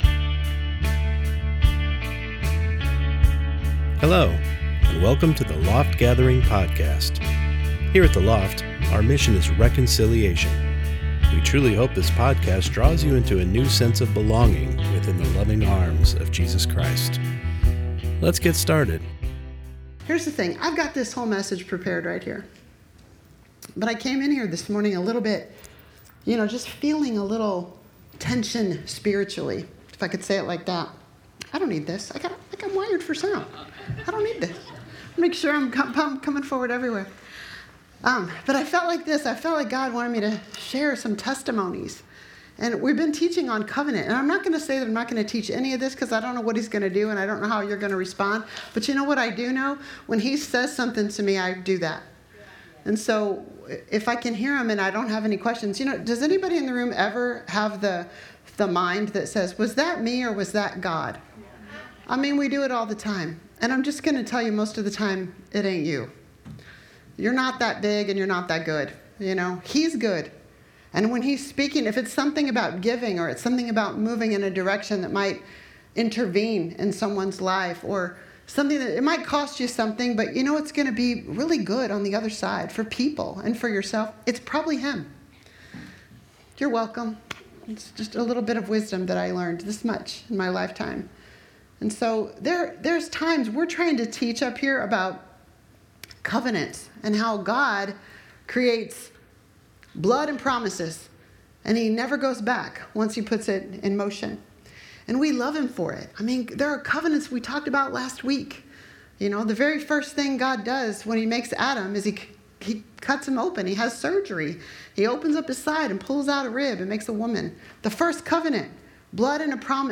Sunday Morning Service Service